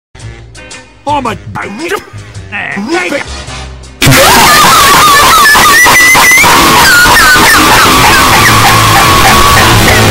Angry Birds Slingshot